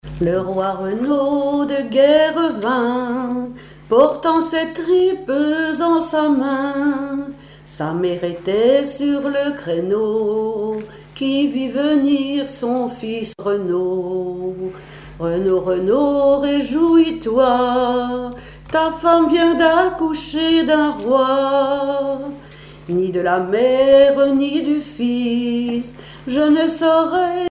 Genre strophique
Chansons et commentaires
Pièce musicale inédite